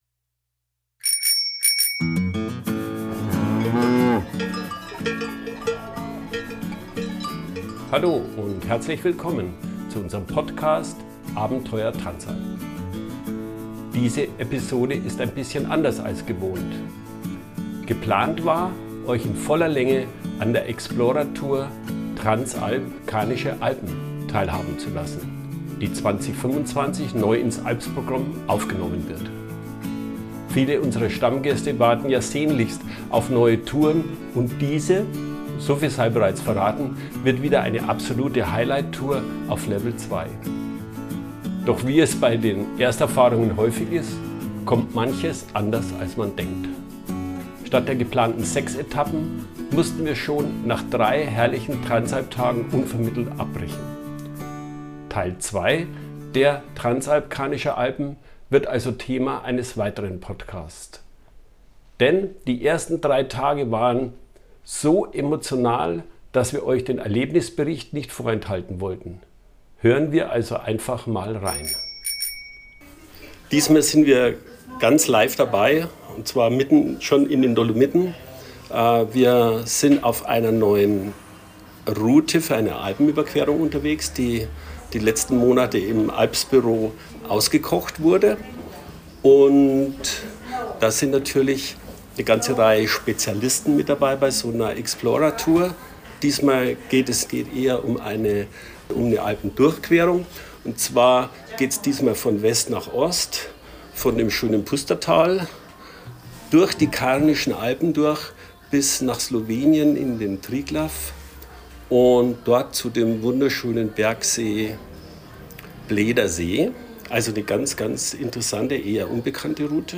In dieser Episode unseres Podcast „Abenteuer Transalp“ seid ihr „live“ bei der Explorer Tour „Transalp Karnische Alpen“ dabei.